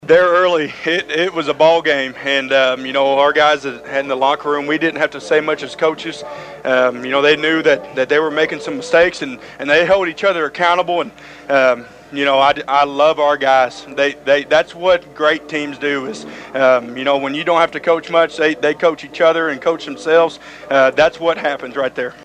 The Thayer Bobcats won the Class 1 District 3 Championship last night, defeating the Marionville Comets 42-21.